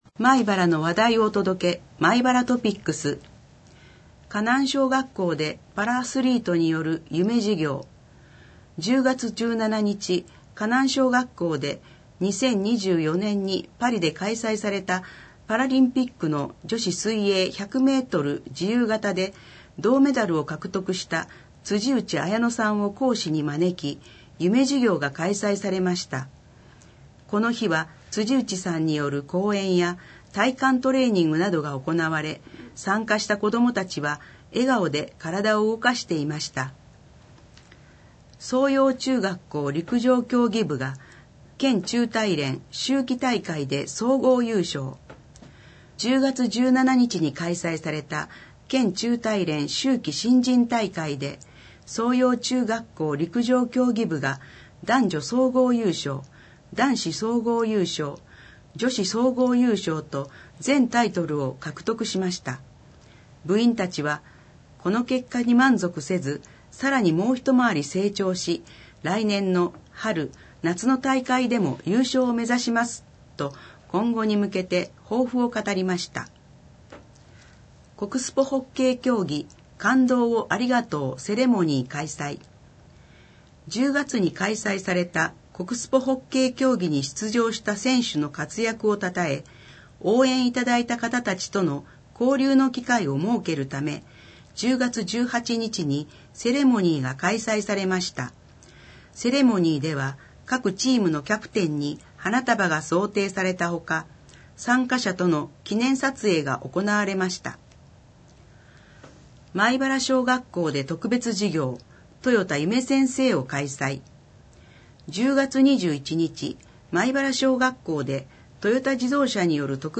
障がい者用に広報まいばらを音訳した音声データを掲載しています。
音声データは音訳グループのみなさんにご協力いただき作成しています。